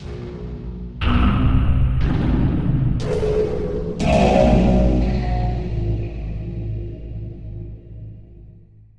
Processed Hits 19.wav